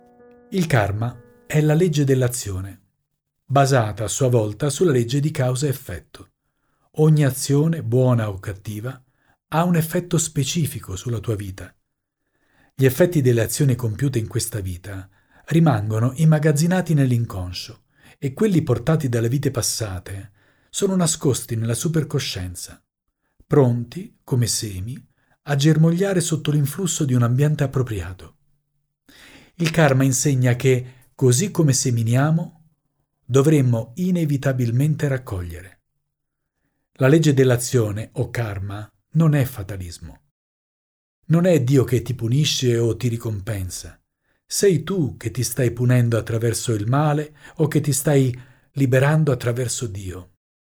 Il Karma... e come smettere di lanciare il boomerang - audiolibro scaricabile - Ananda Edizioni - Semi di Luce